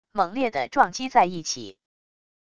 猛烈的撞击在一起wav音频